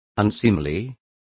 Complete with pronunciation of the translation of unseemly.